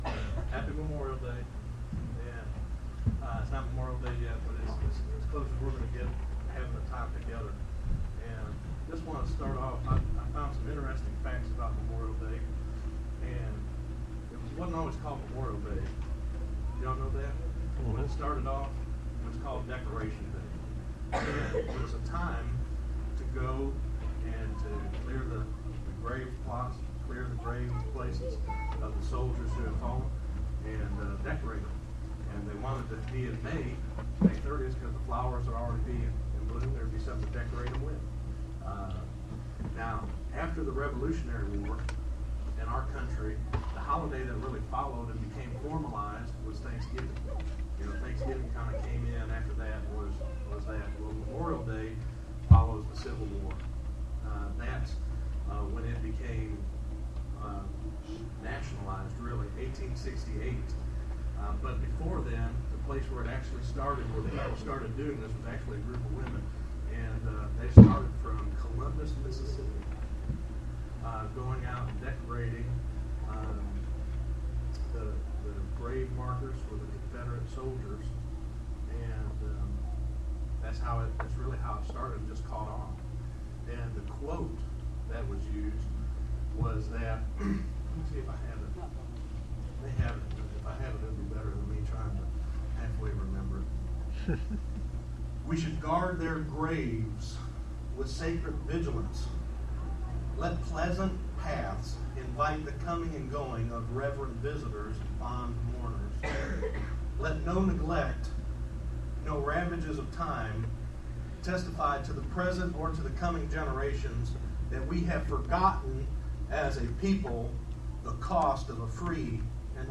5_27_12_sermon.mp3